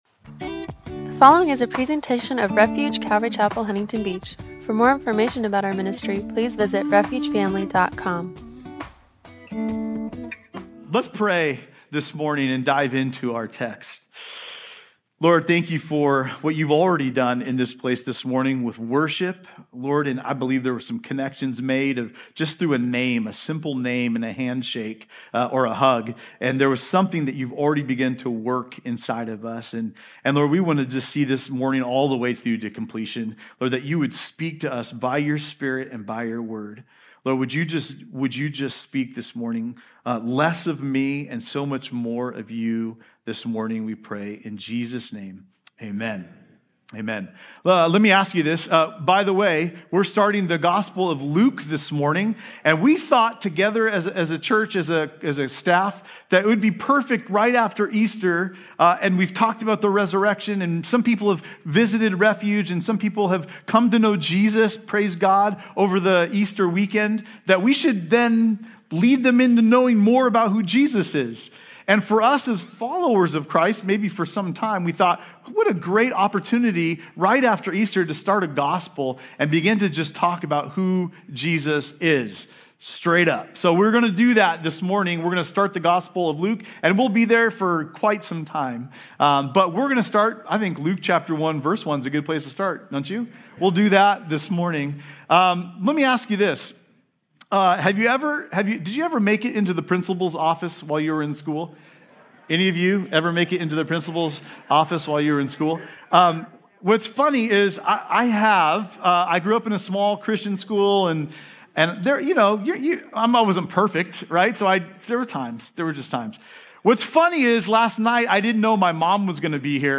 “Where It All Begins”-Luke 1:1-25 – Audio-only Sermon Archive
Service Type: Sunday Morning